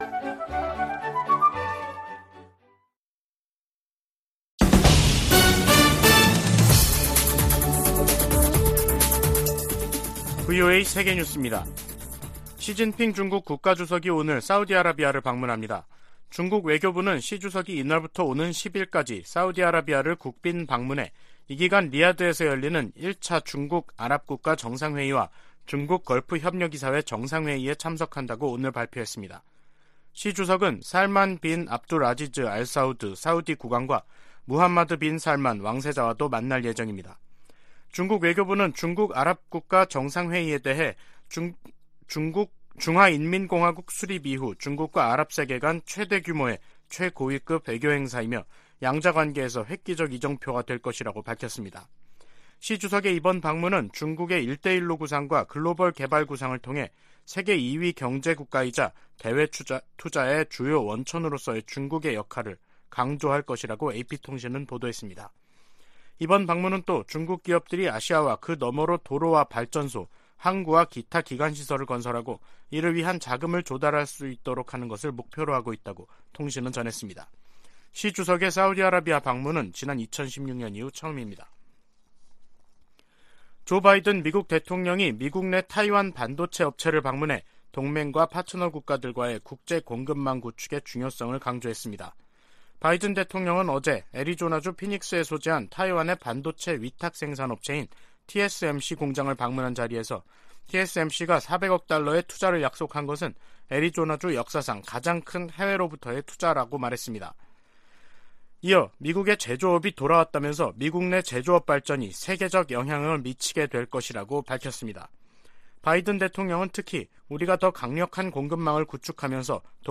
VOA 한국어 간판 뉴스 프로그램 '뉴스 투데이', 2022년 12월 7일 2부 방송입니다. 국무부가 중국과 러시아에 유엔 안보리 대북 결의에 따른 의무를 이행하라고 거듭 촉구했습니다. 미국과 호주가 북한의 불법적인 핵과 탄도미사일 프로그램을 해결하겠다는 의지를 거듭 확인하고 국제사회에 유엔 안보리 결의 준수를 촉구했습니다.